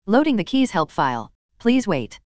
Keys.wav